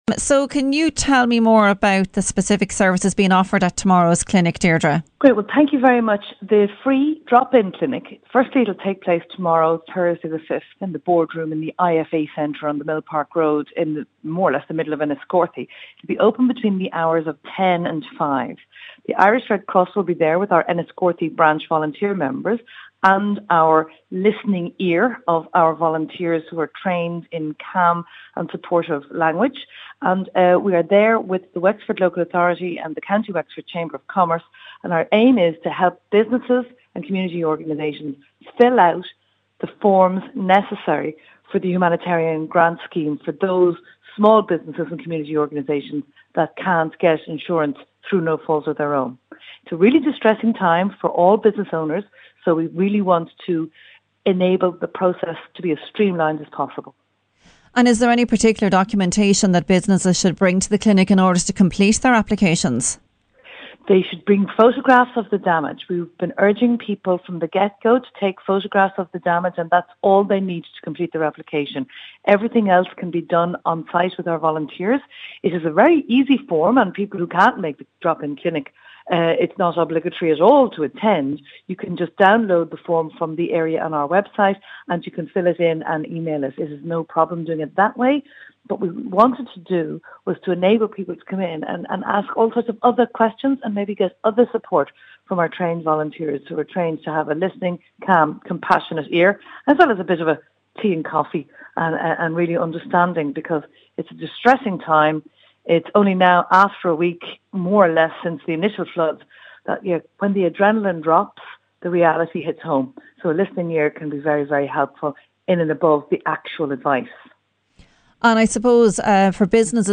Speaking to south east radio news with all the details